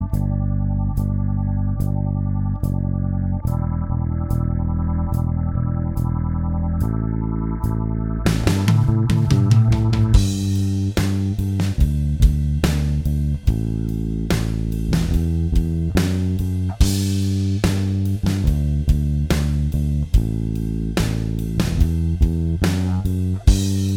Minus All Guitars Indie / Alternative 4:01 Buy £1.50